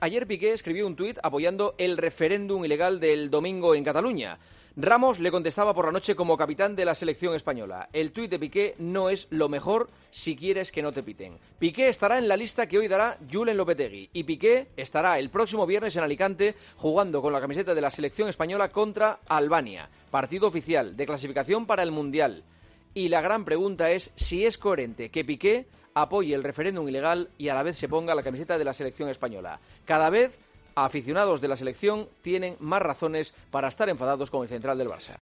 El comentario de Juanma Castaño
¿Es coherente que el central del Barça apoye el referéndum ilegal y a la vez se ponga la camiseta de la Selección? Escucha el comentario de Juanma Castaño en 'Herrera en COPE'.